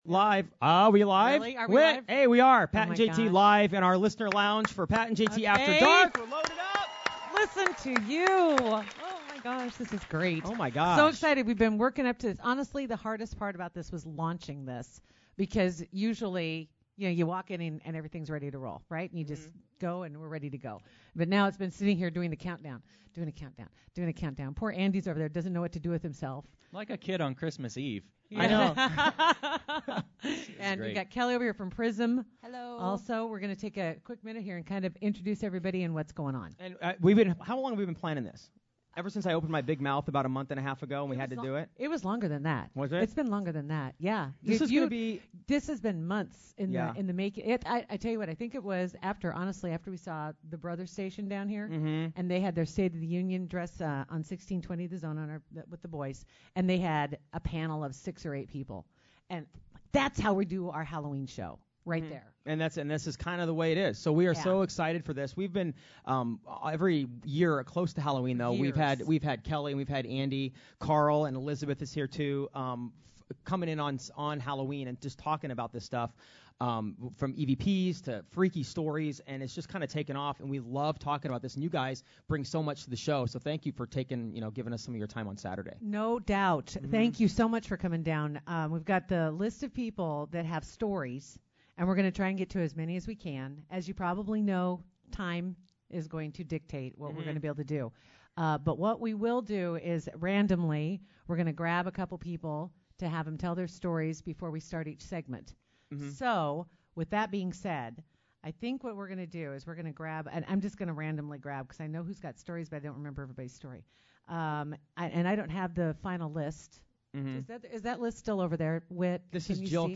Three EVPs from the investigations were played during the interview.>> Listen to the radio interview here